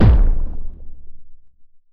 Gorilla Tag Join Sound Button: Meme Soundboard Unblocked